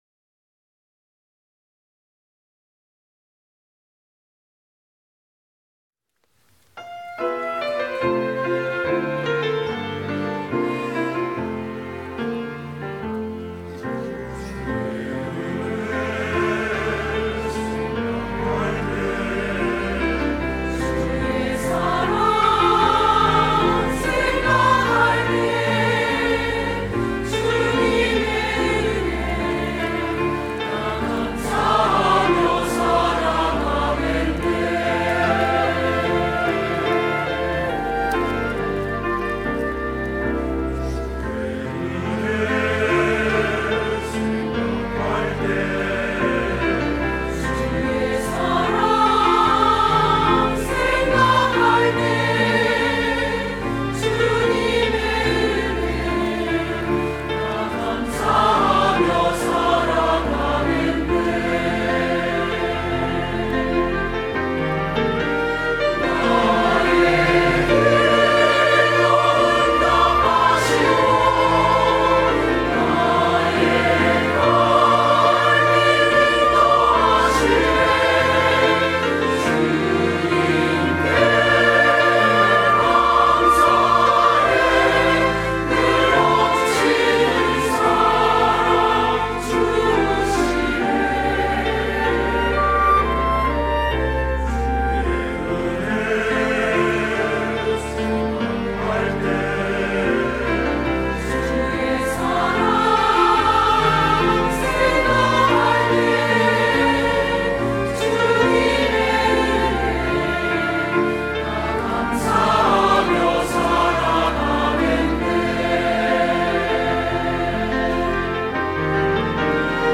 갈릴리